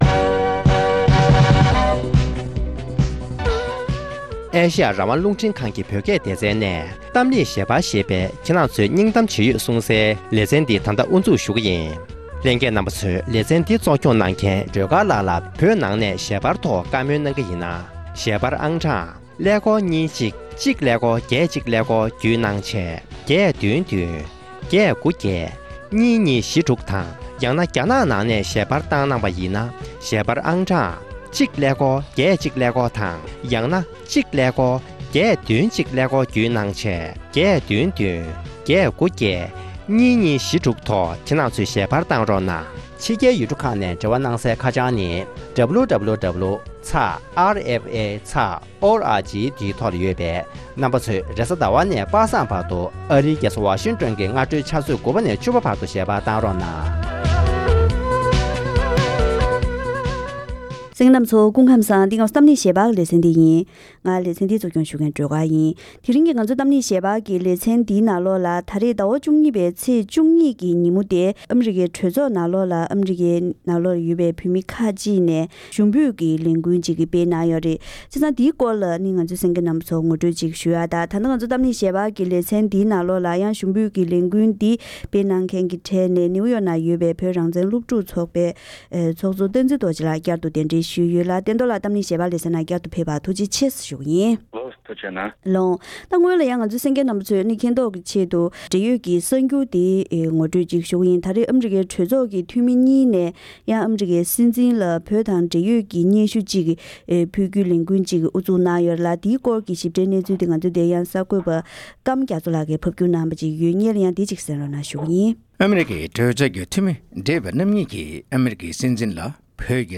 འབྲེལ་ཡོད་མི་སྣར་བཀའ་མོལ་ཞུས་པ་ཞིག་ལ་གསན་རོགས་ཞུ༎